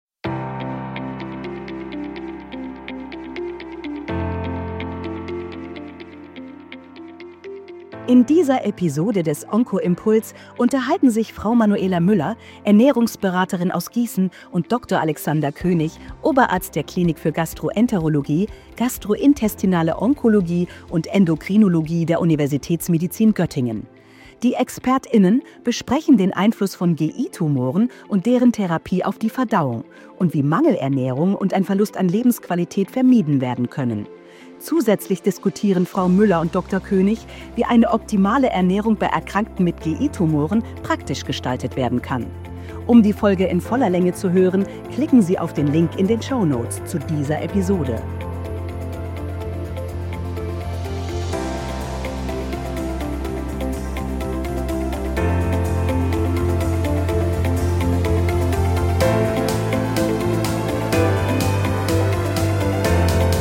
Gespräch zu Ernährung bei gastrointestinalen Tumoren zwischen